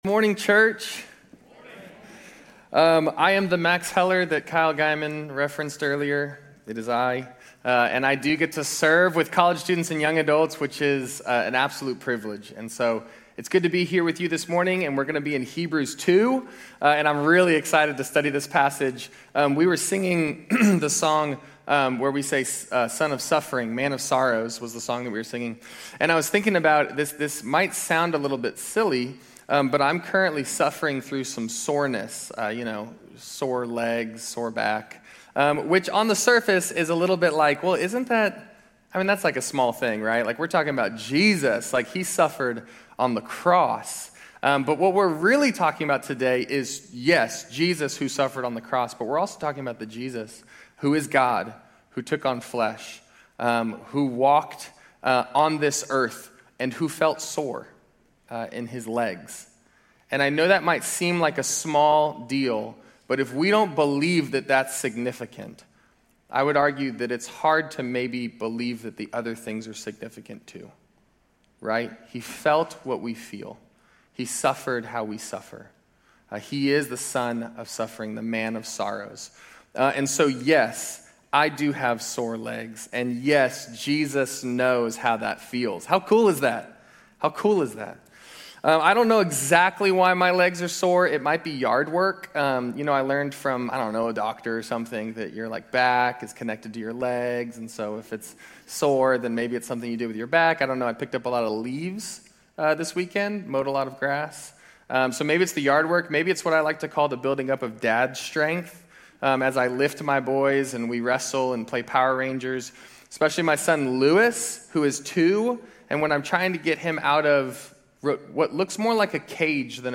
Grace Community Church University Blvd Campus Sermons 9_28 University Blvd Campus Sep 29 2025 | 00:40:30 1x 00:00 / 00:40:30 Subscribe Share RSS Feed Share Link Embed